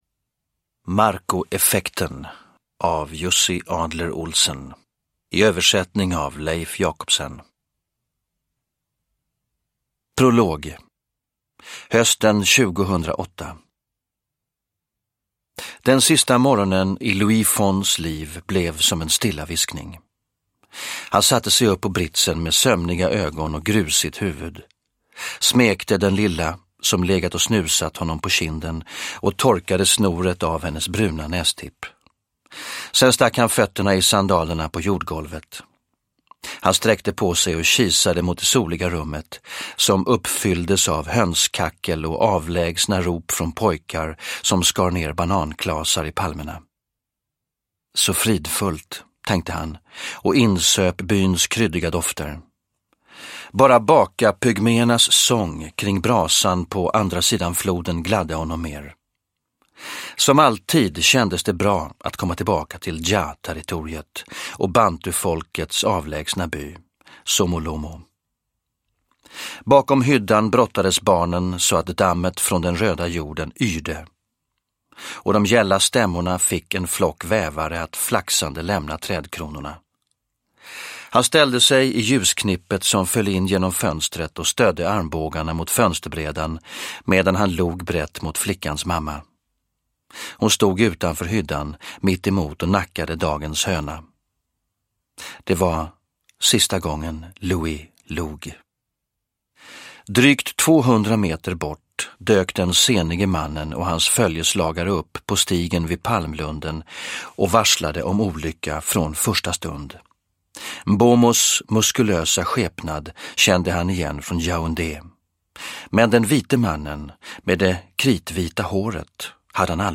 Produkttyp: Digitala böcker
Uppläsare: Stefan Sauk